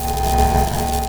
Magic_ElectricEnergyLoop02.wav